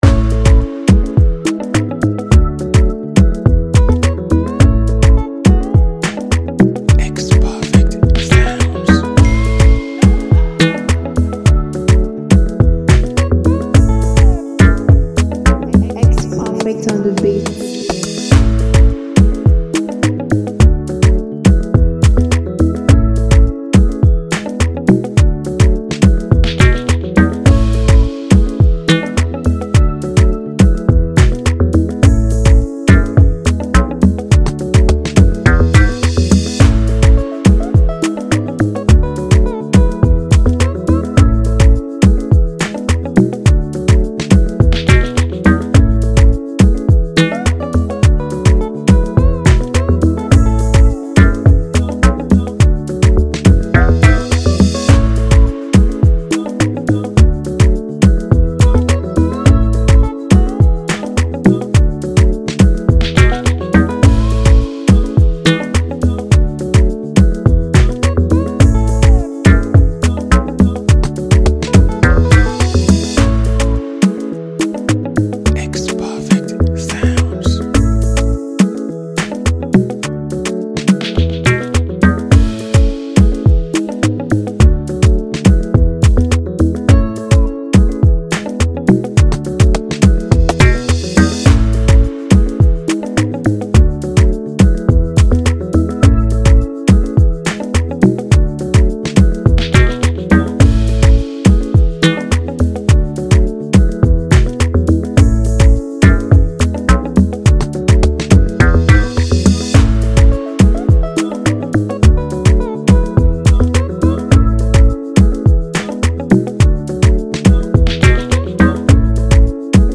Download instrumental free beat mp3 below…